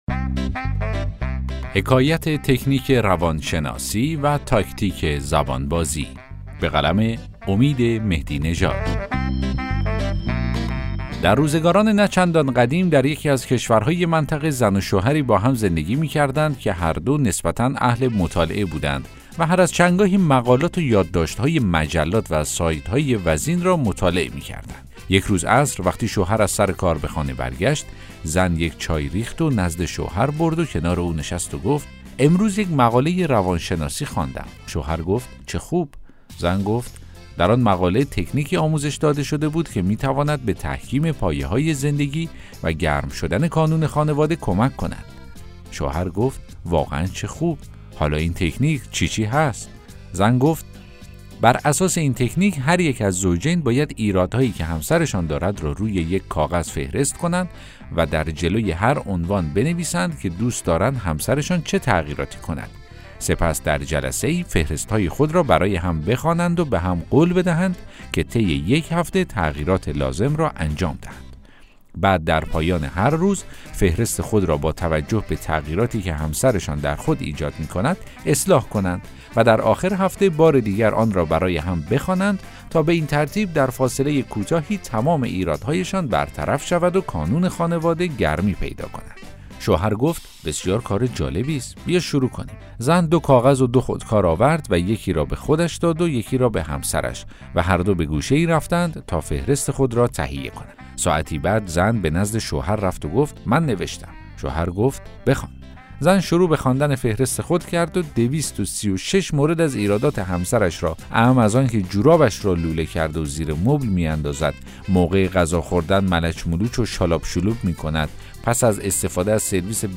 داستان صوتی: حکایت تکنیک روان شناسی و تاکتیک زبان بازی